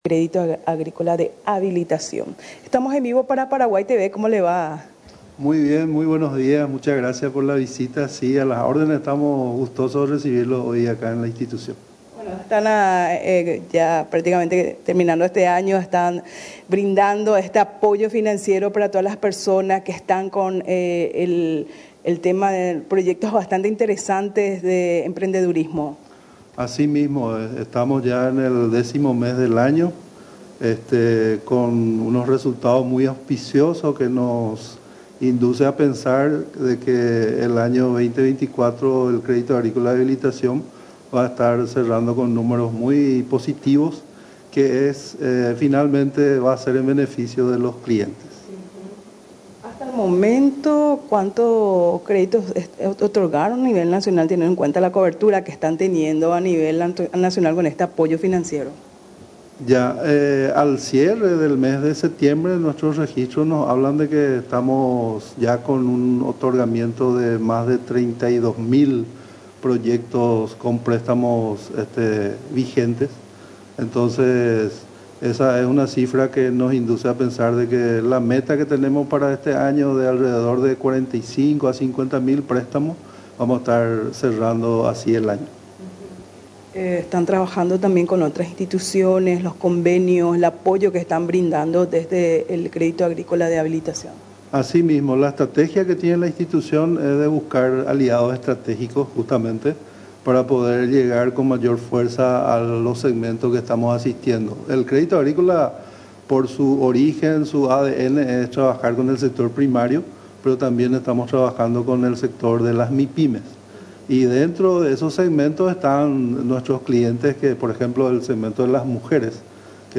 Durante la entrevista a Radio Nacional del Paraguay, explicó los detalles y el modo de participar en la citada capacitación organizada por la institución estatal.